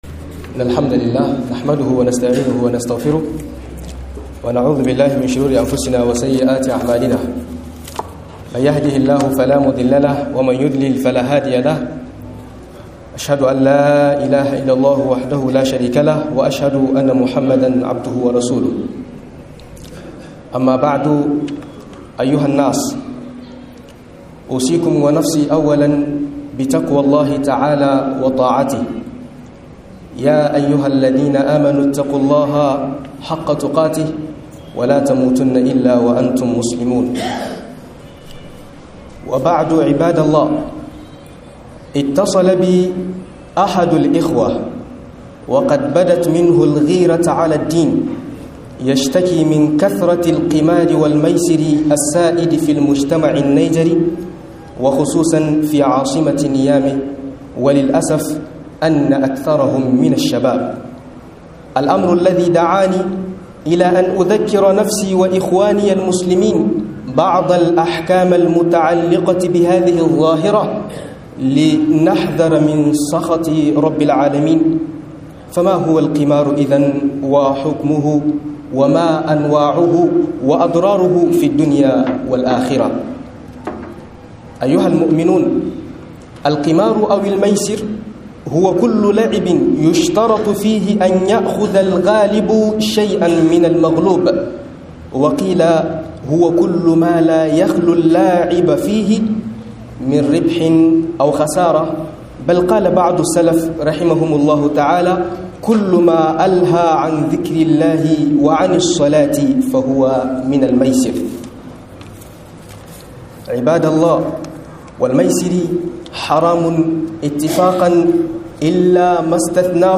Yaduwar wasanin caca a Niger_Trim - MUHADARA